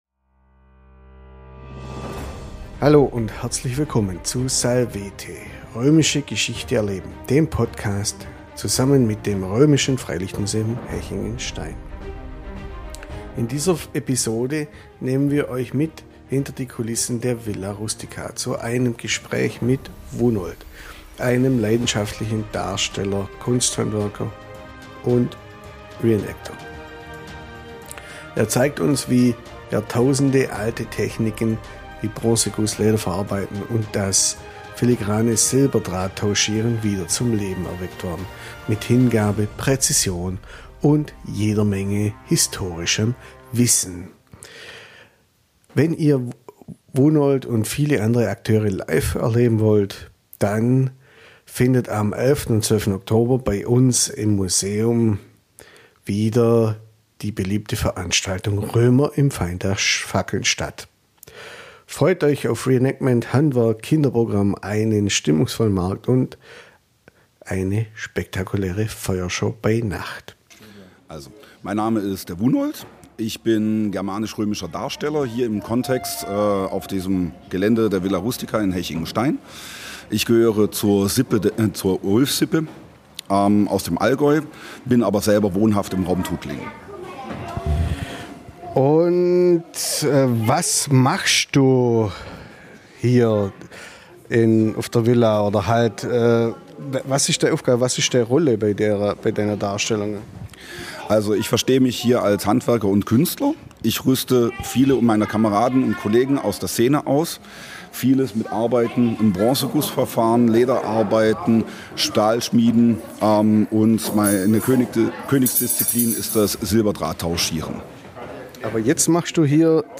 Ein Gespräch über Feuer, Präzision, Ausdauer – und über die Freude, ein uraltes Handwerk in die Gegenwart zu holen.